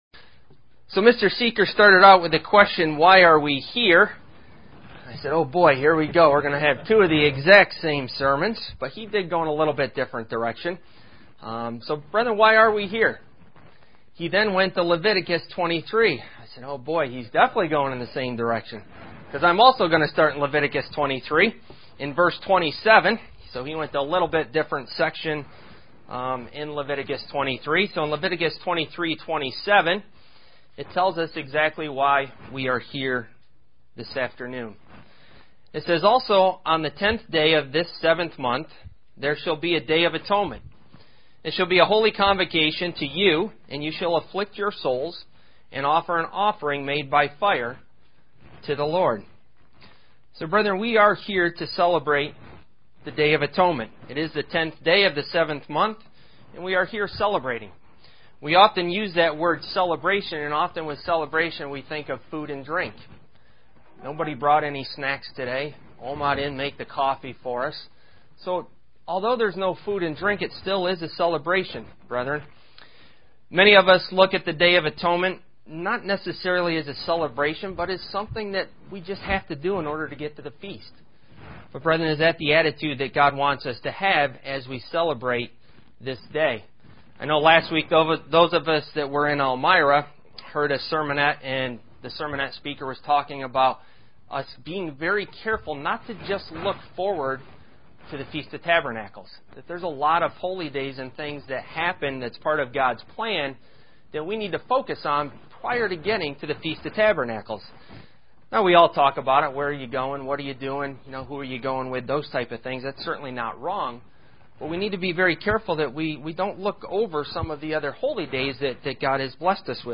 UCG Sermon Studying the bible?
Given in Elmira, NY